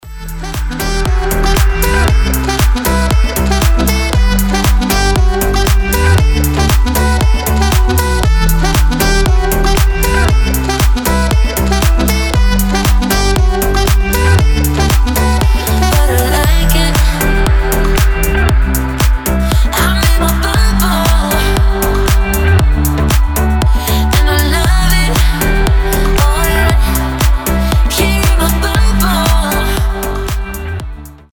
• Качество: 320, Stereo
позитивные
deep house
Саксофон
расслабляющие